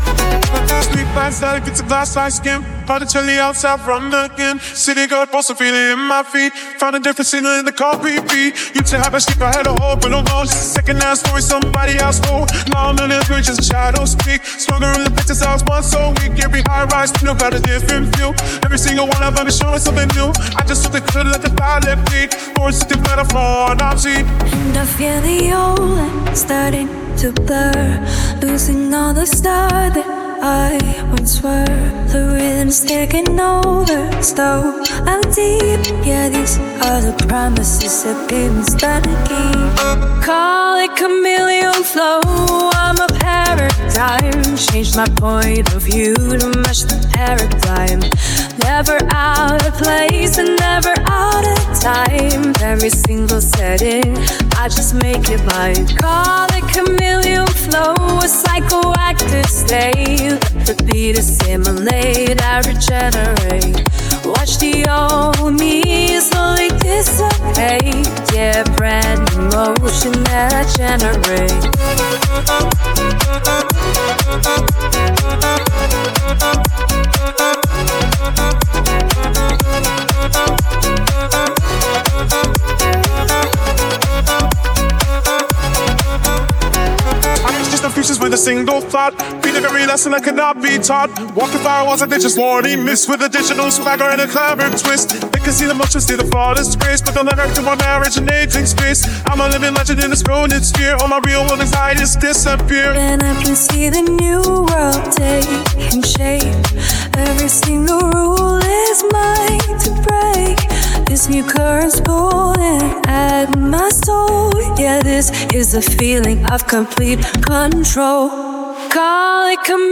This song has not, and will intentionally not, use a traditional DAW or other non-AI tool. Every byte will be generated by a model.
• The layered harmony in the choruses
• C# Dorian key
• Timbre and processing of the male voice
AI Music Generation